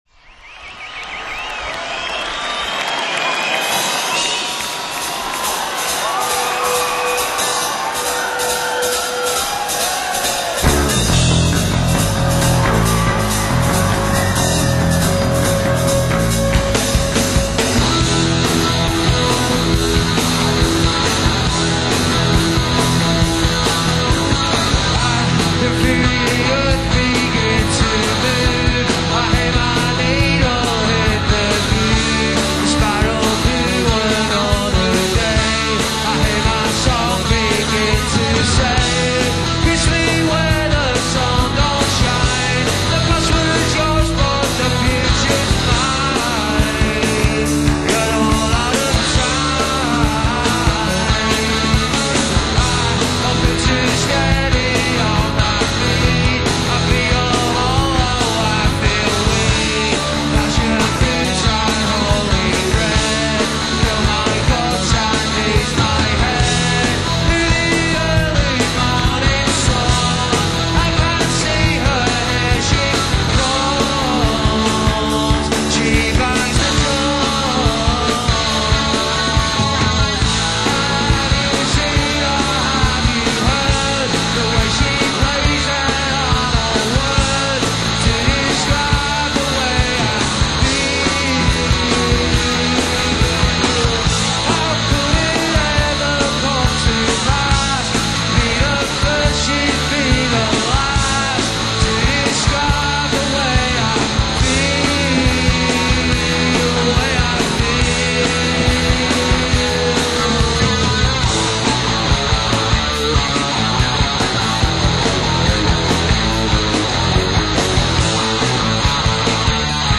Live at Town & Country
recorded live at the Town & Country in 1995
quasi-retro/neo-psych/indie/alternative sound